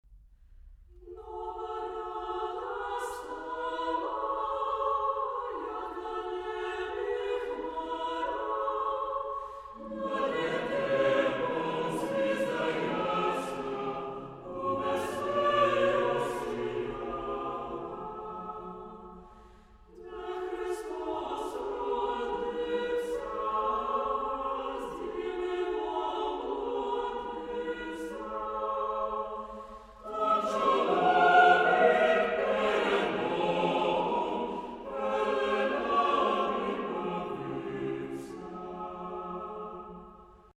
Carol.
Orthodox song.
Tonality: F sharp minor